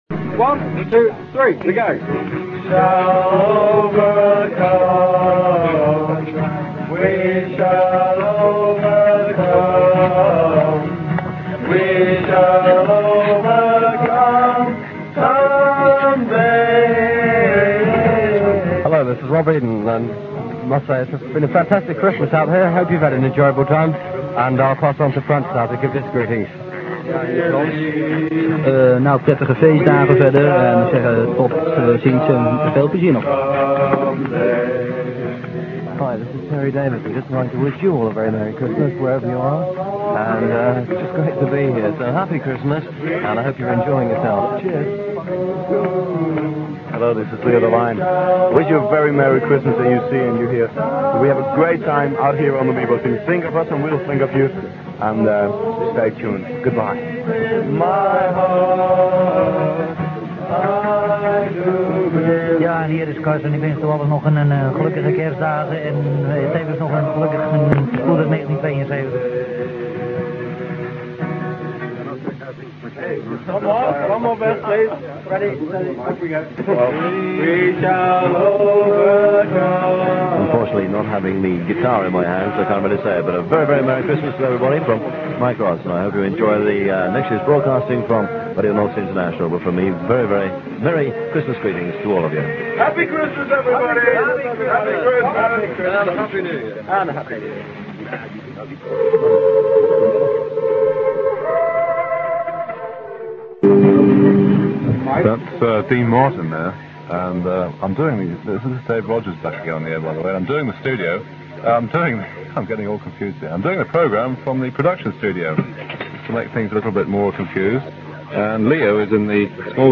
having a Christmas night party on RNI with a singalong version of We Shall Overcome.